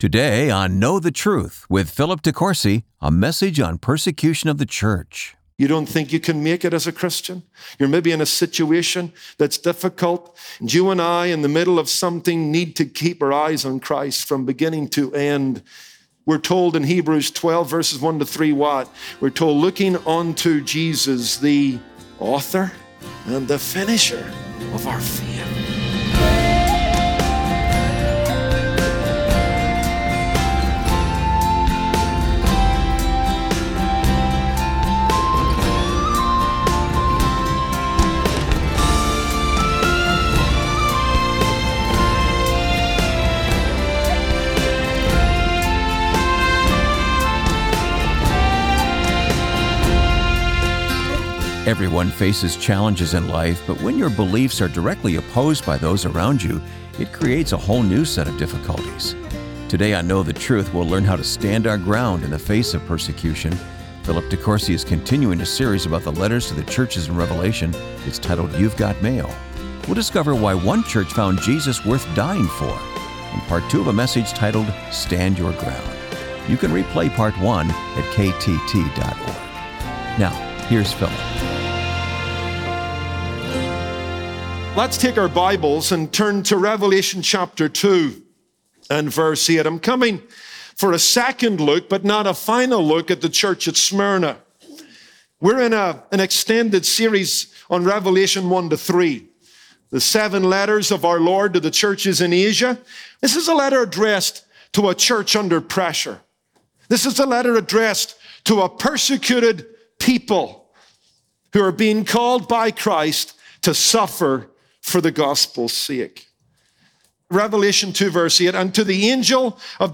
Well, it was certainly true of the first century church at Smyrna who courageously followed Jesus, even in the face of death. On this Wednesday broadcast